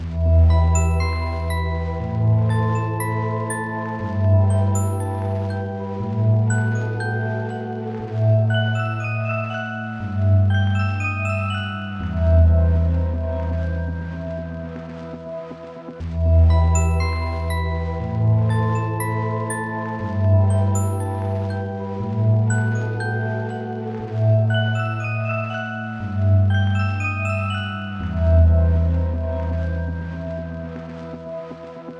古老的钟声
Tag: 120 bpm Hip Hop Loops Bells Loops 5.38 MB wav Key : Unknown